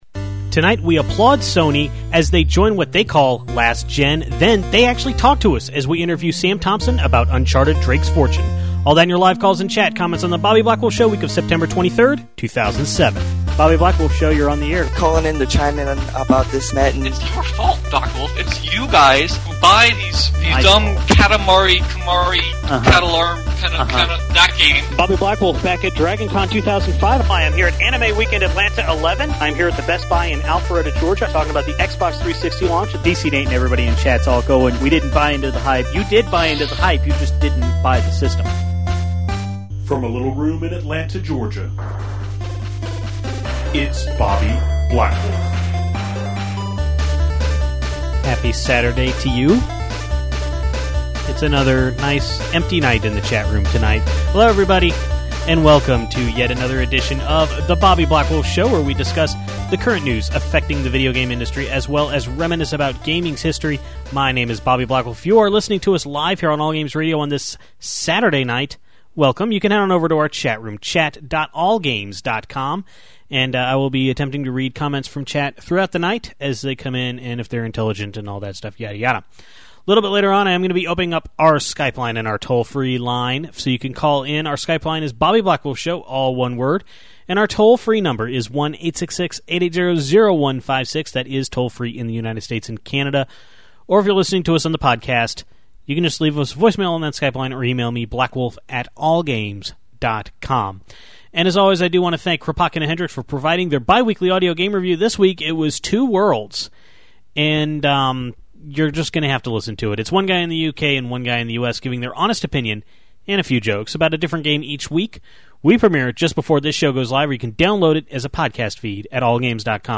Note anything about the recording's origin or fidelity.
We give some early impressions on two Nintendo DS games, the first one being Drawn to Life. Then, we do a live demo of Jam Sessions, demonstrating it's strengths and weaknesses. The Tokyo Game Show was this past week, and one of the big announcements was Sony bringing rumble to the PS3.